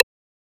rim2.wav